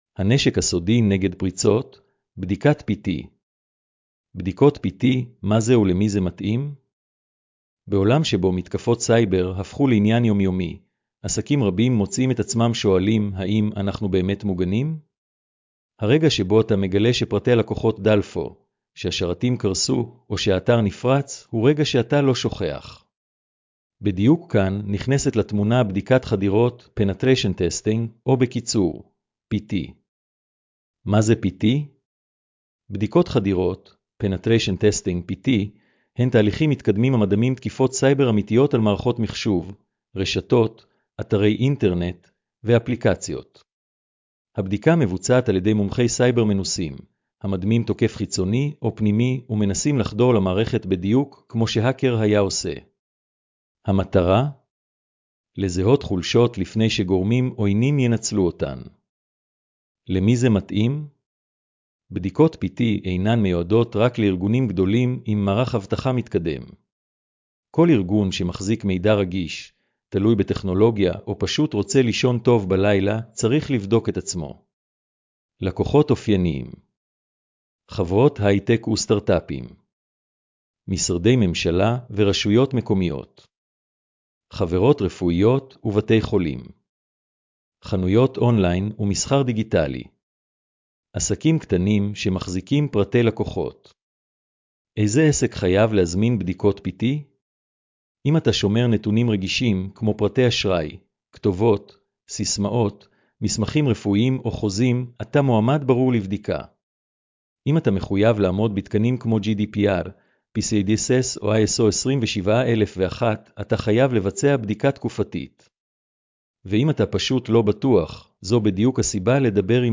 השמעת המאמר לכבדי ראייה